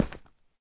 sounds / step / stone1.ogg
stone1.ogg